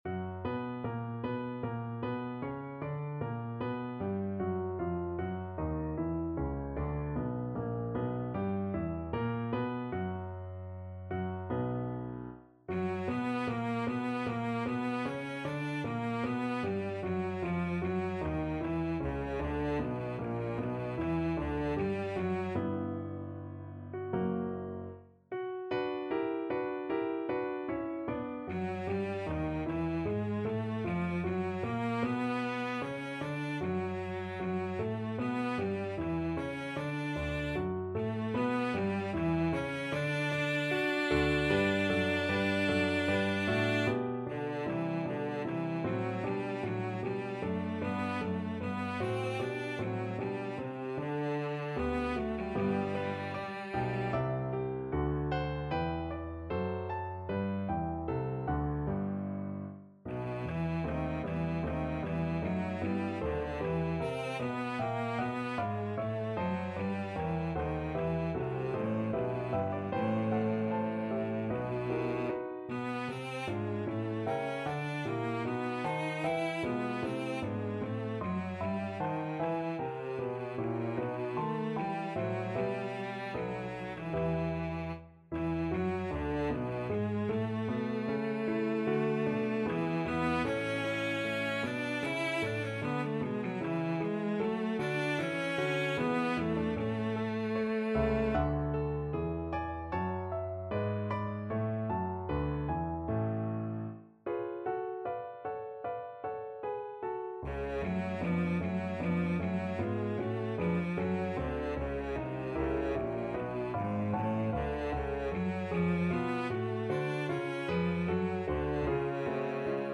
Cello
4/4 (View more 4/4 Music)
Larghetto (=76)
B minor (Sounding Pitch) (View more B minor Music for Cello )
Classical (View more Classical Cello Music)